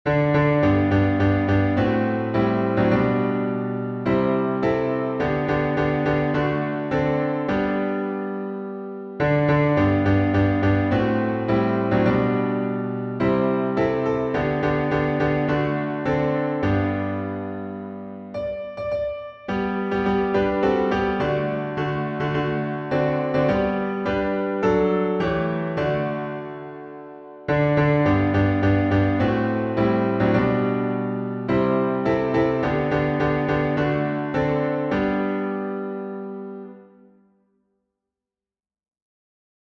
Look and Live — alternate chording.